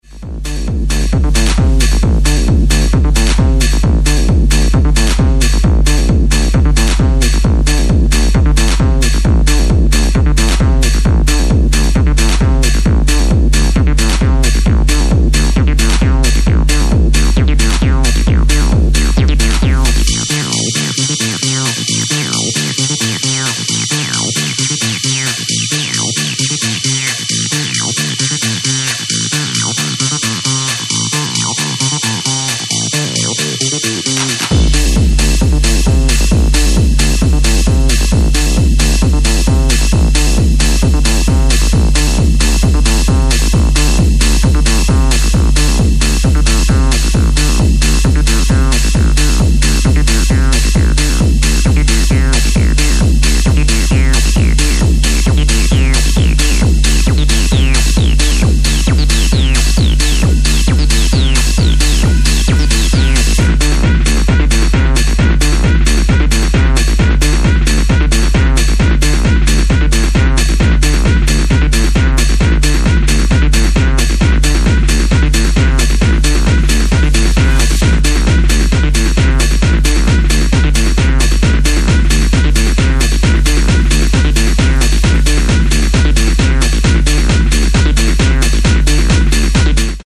Electronix Techno Acid